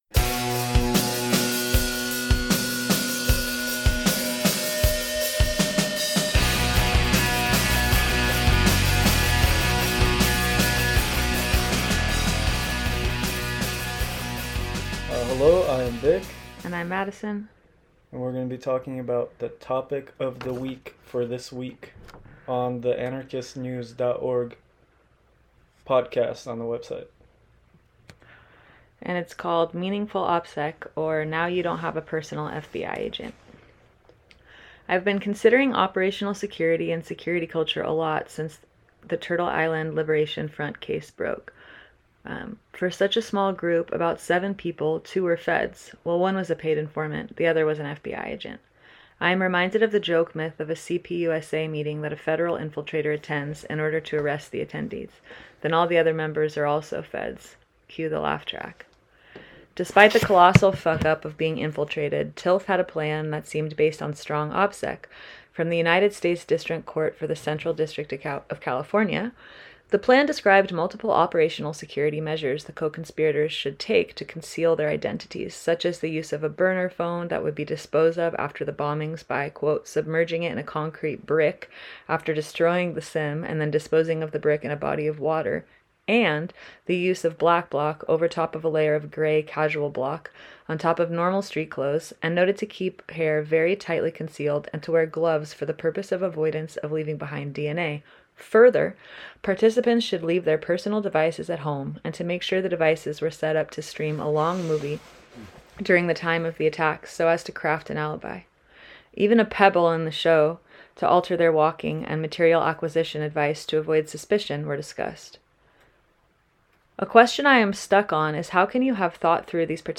Topic of the Week: Meaningful OpSec a conversation